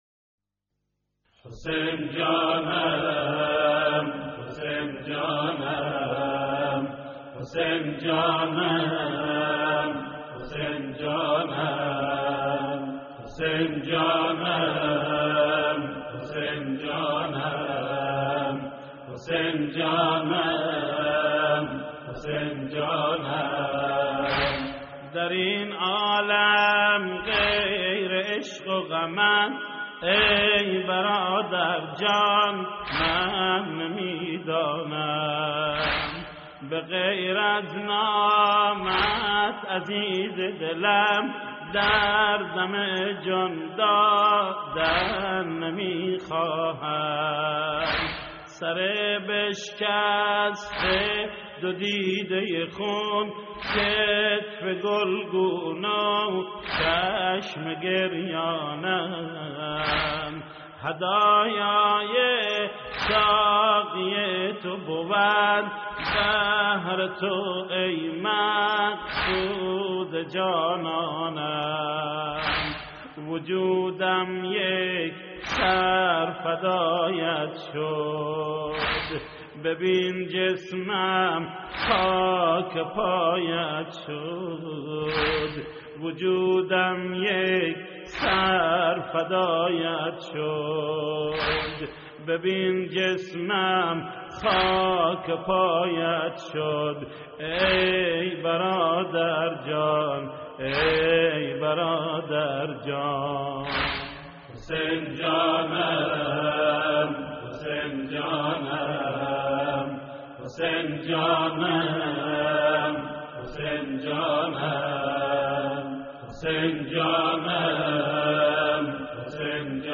استديو فارسي